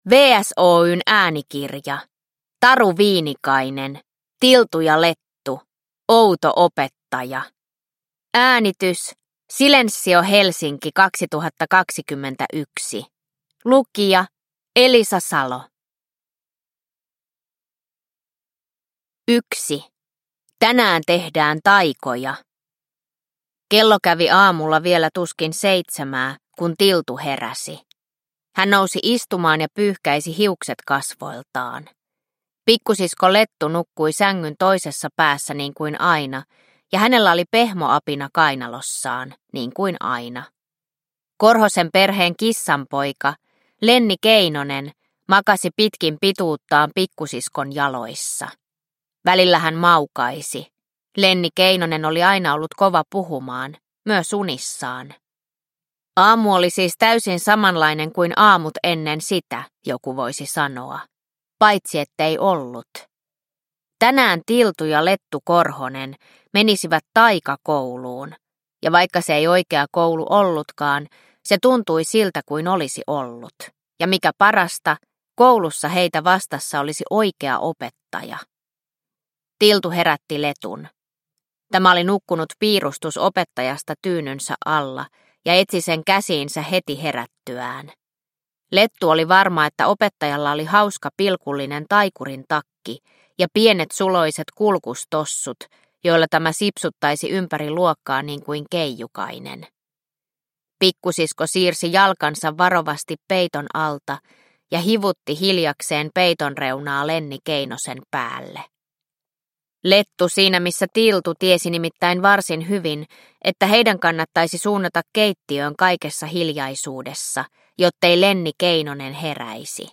Tiltu ja Lettu - Outo opettaja – Ljudbok – Laddas ner